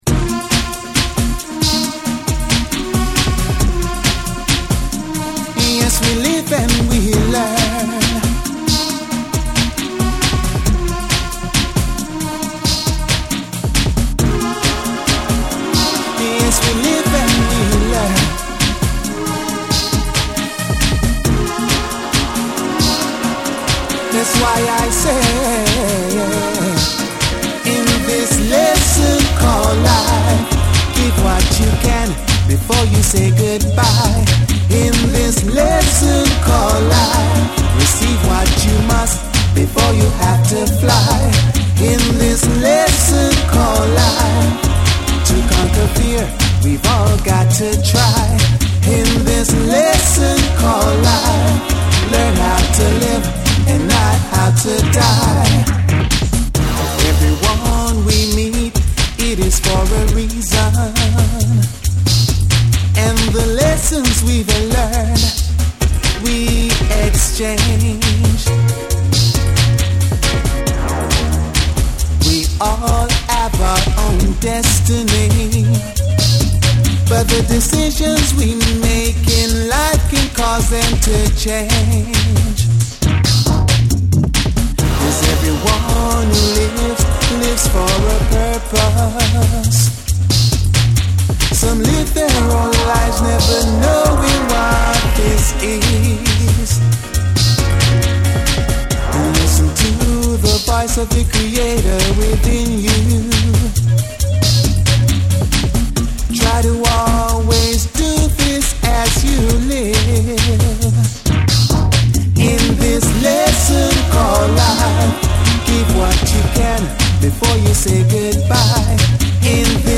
BREAKBEATS / REGGAE & DUB